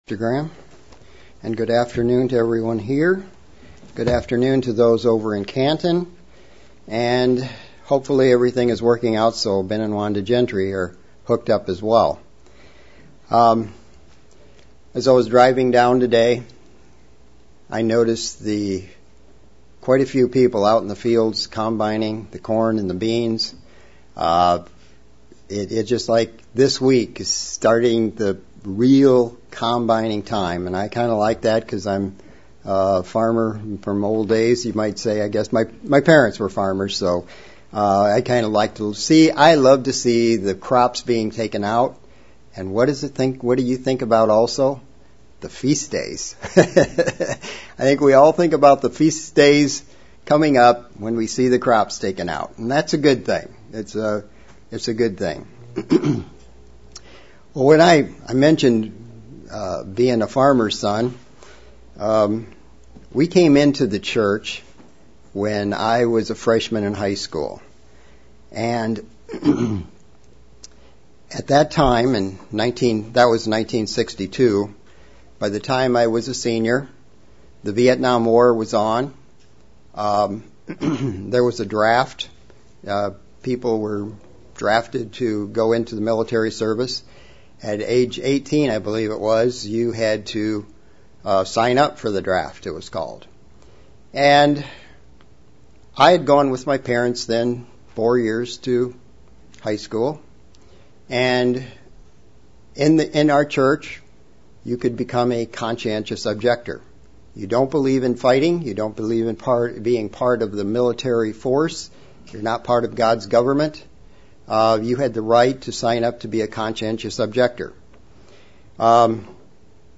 A true heart of Obedience UCG Sermon Studying the bible?